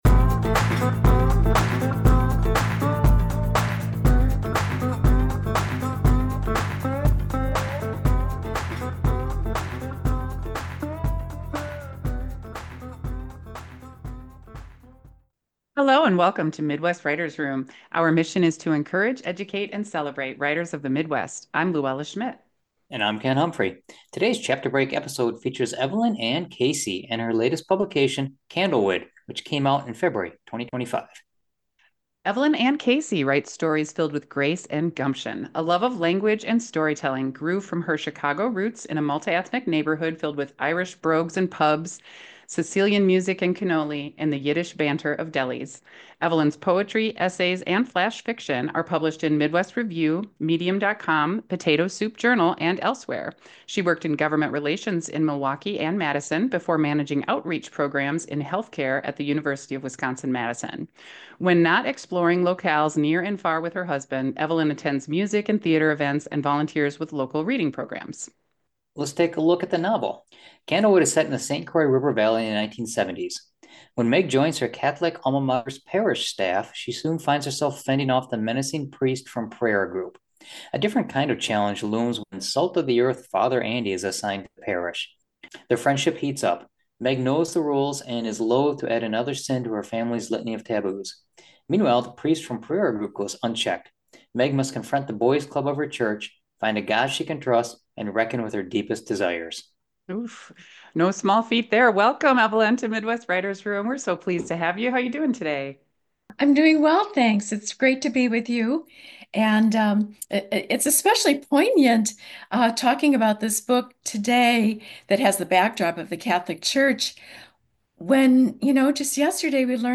Podcast: Interview on Midwest Writers Room